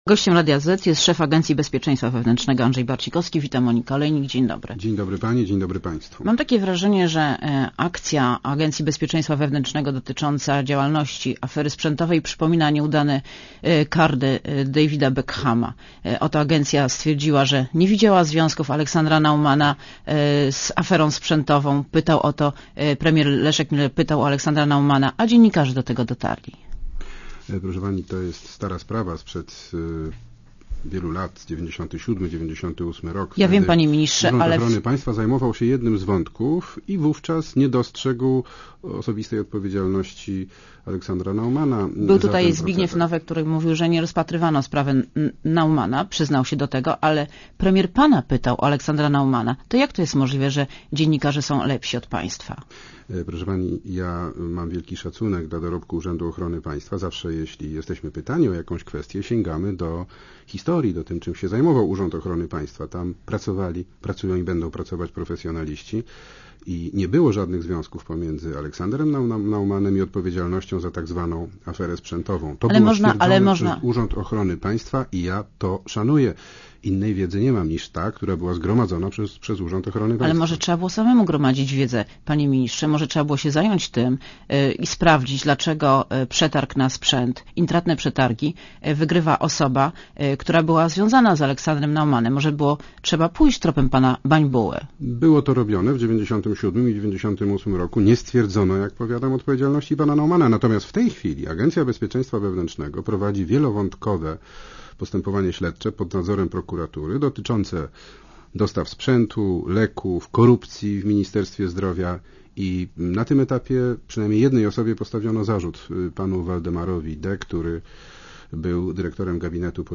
Posłuchaj wywiadu Gościem Radia Zet jest Andrzej Barcikowski, szef ABW .